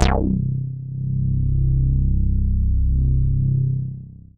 C3_moogy.wav